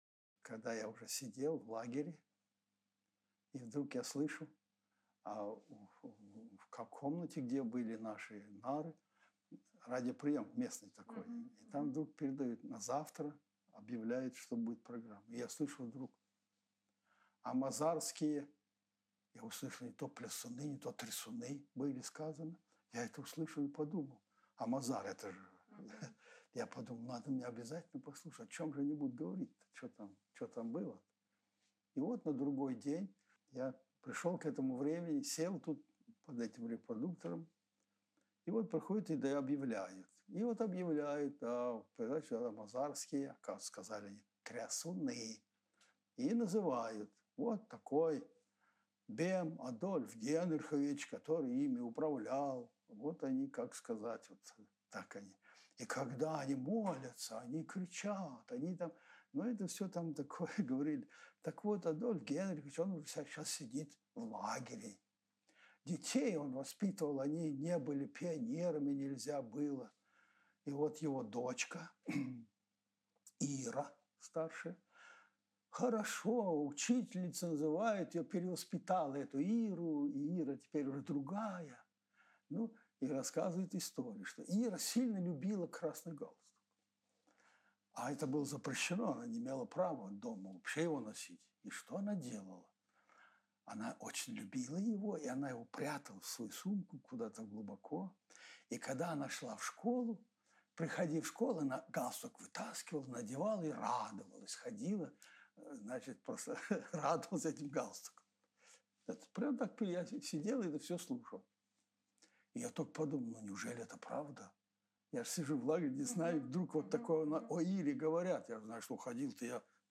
Interviewsequenz Propaganda